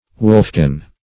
\Wolf"kin\